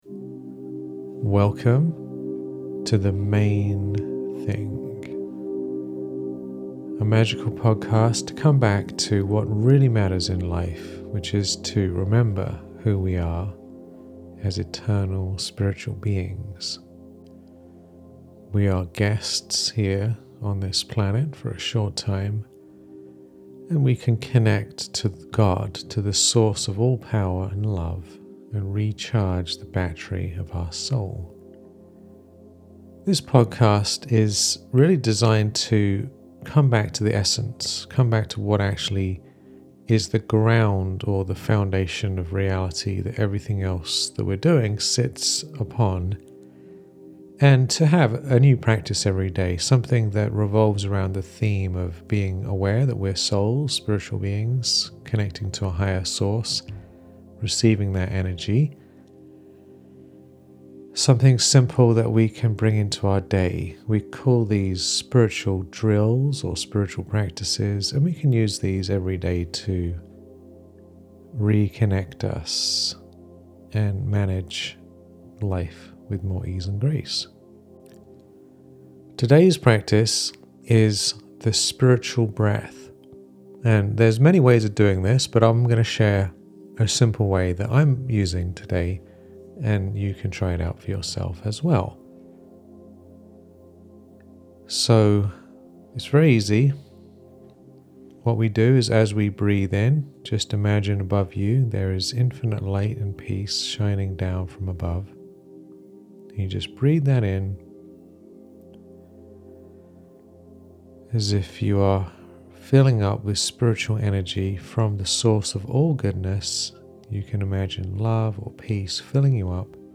The Main Thing (new daily meditation podcast)